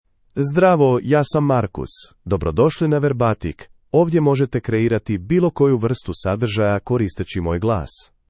Marcus — Male Croatian (Croatia) AI Voice | TTS, Voice Cloning & Video | Verbatik AI
MaleCroatian (Croatia)
MarcusMale Croatian AI voice
Voice sample
Marcus delivers clear pronunciation with authentic Croatia Croatian intonation, making your content sound professionally produced.